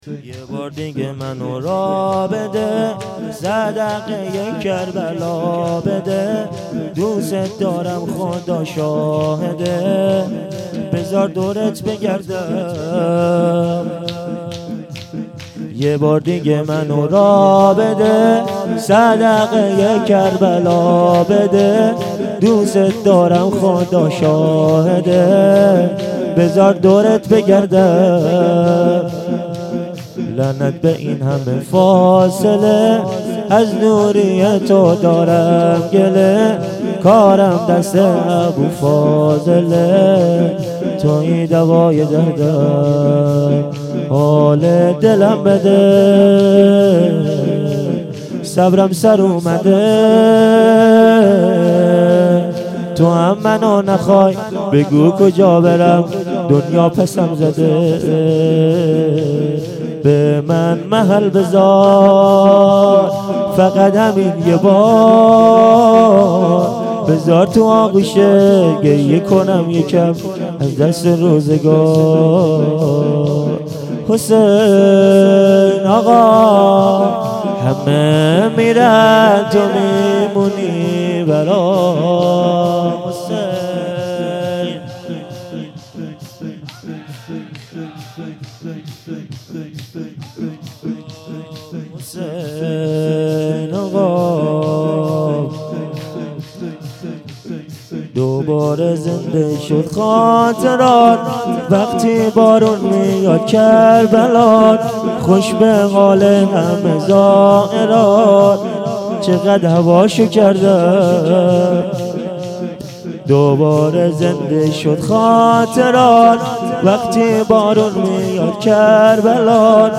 شور
مراسم هفتگی